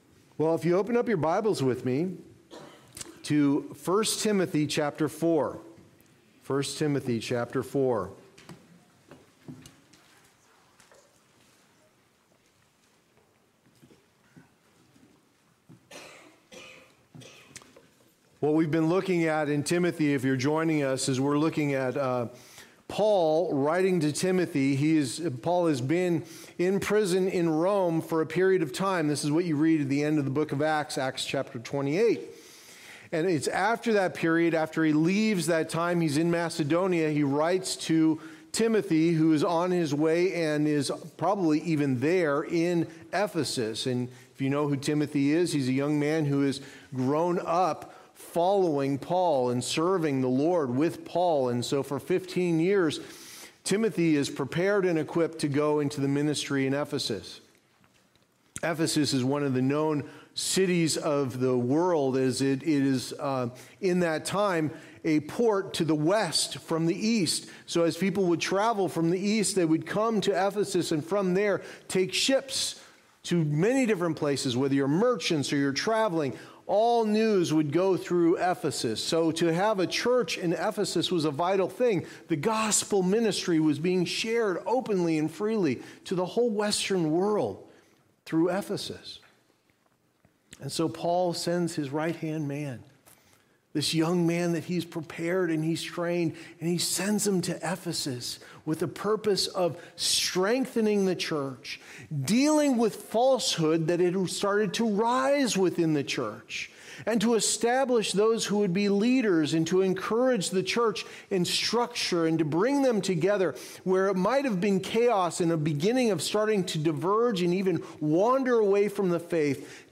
Guard the Gospel & Shepherd the Flock Passage: I Timothy 4:1-5 Services: Sunday Morning Service Download Files Notes Previous Next